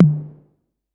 Tr8 Tom 04.wav